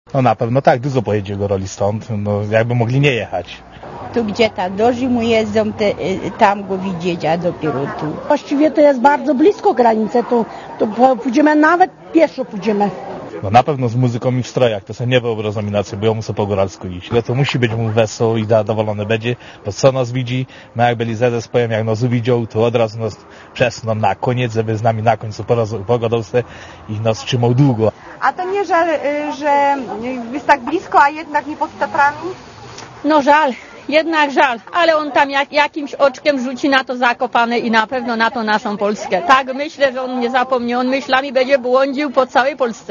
goralepapiez.mp3